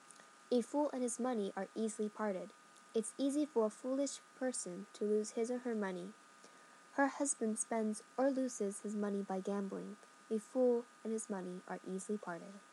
英語ネイティブによる発音は以下のリンクをクリックしてください。